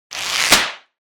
13. Звук оборванного каната, порвался
kanat-1.mp3